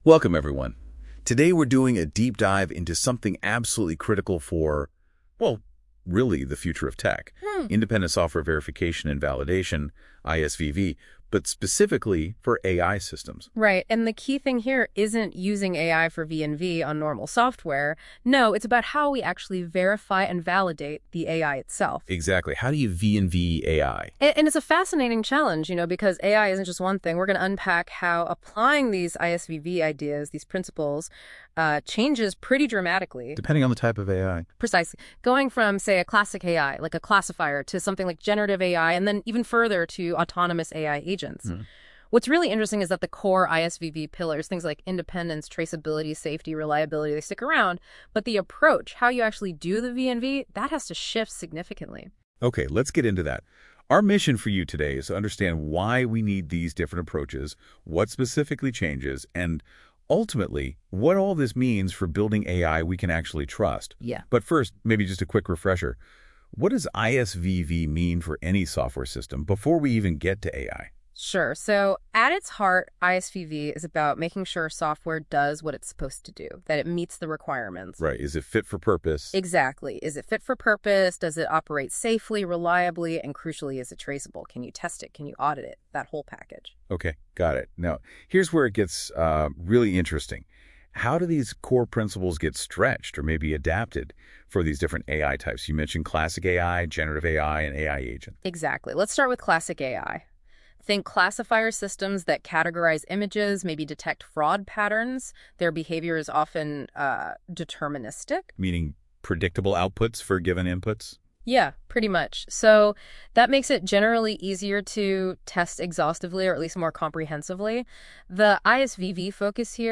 accompanying audio has been generated using Google Gemini.